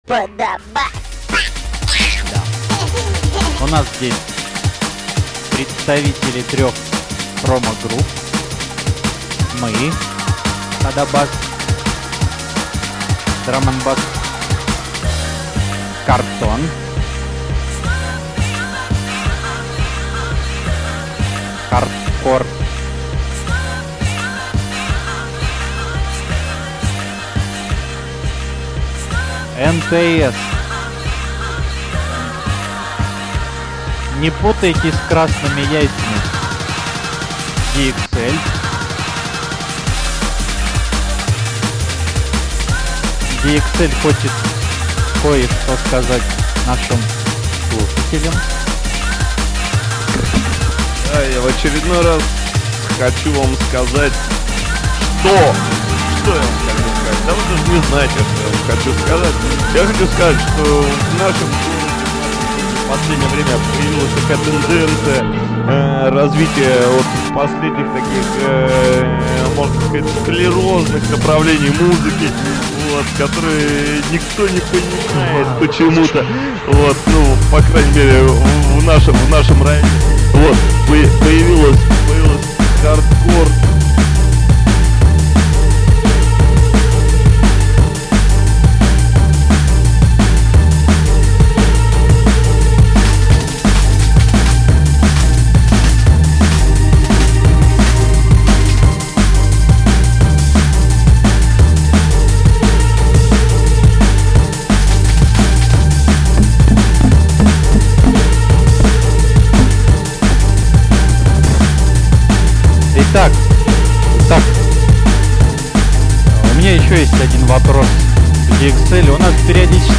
Drum & Bass
Mainstream Hardcore